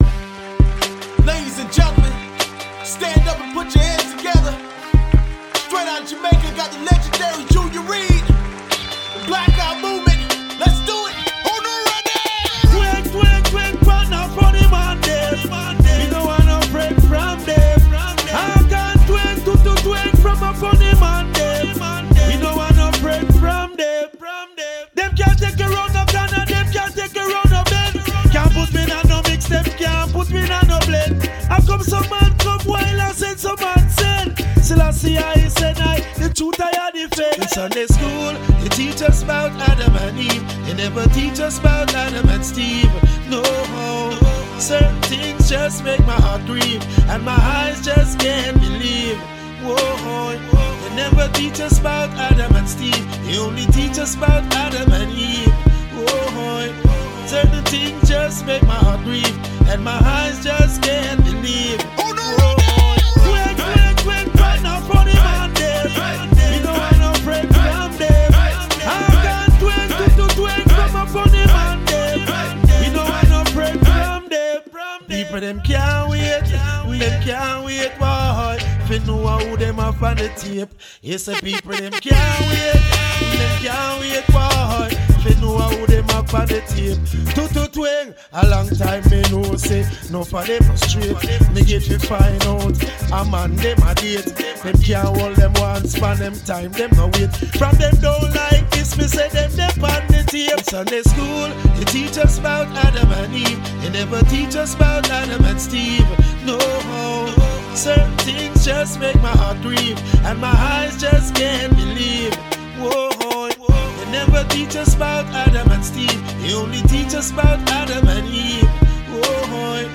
Жанр: reggae / dancehall